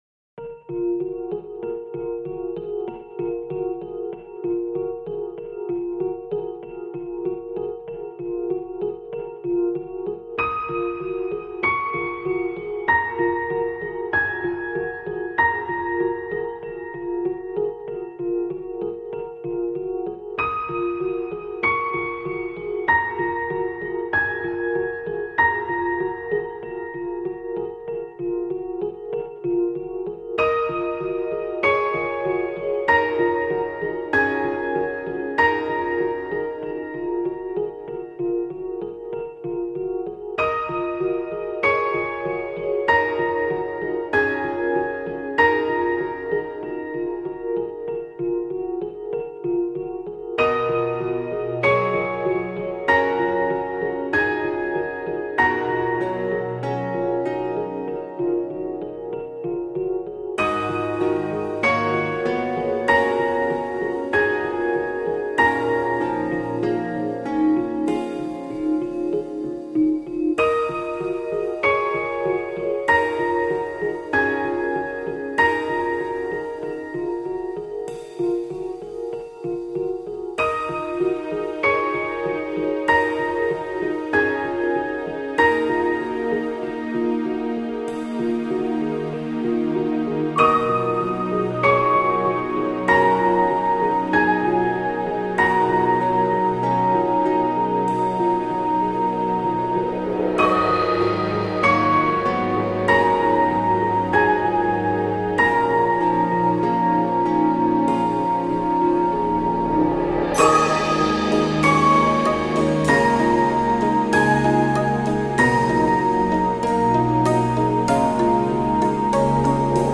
经典配乐 你是第11752个围观者 0条评论 供稿者： 标签：, , ,